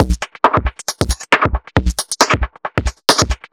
Index of /musicradar/uk-garage-samples/136bpm Lines n Loops/Beats
GA_BeatFilterB136-02.wav